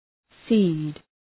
Shkrimi fonetik {si:d}